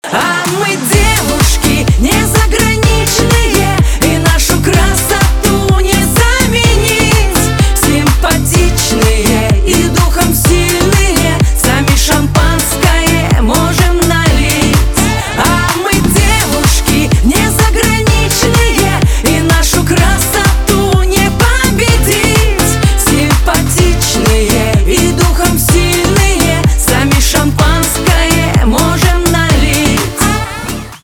Поп